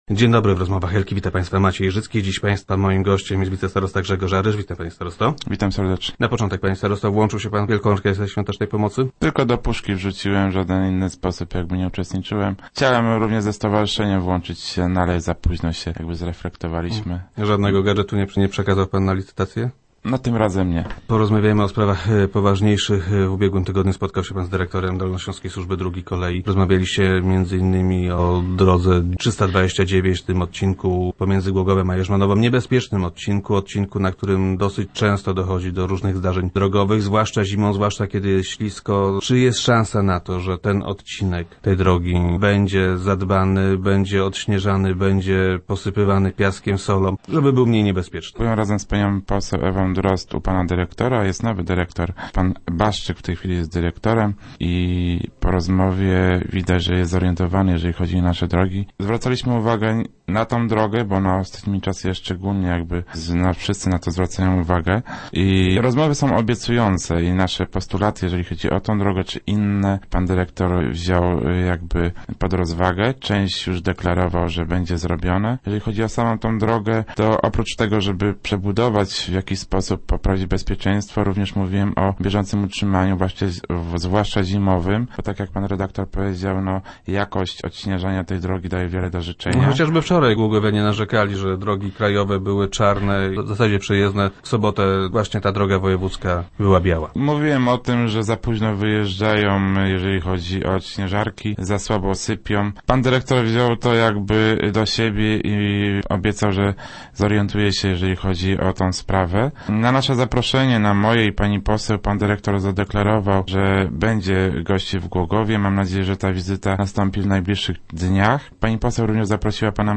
- Rozmawialiśmy nie tylko o ewentualnej przebudowie tej drogi. Mówiłem również o jej zimowym utrzymaniu. Jakość odśnieżania nie jest najlepsza. Wspominałem o tym, że drogowcy zbyt późno wyjeżdżają. Zbyt mało sypią piasku i soli. Pan dyrektor przyjął moje zastrzeżenia i obiecał, że sprawą się zajmie - mówił wicestarosta na radiowej antenie.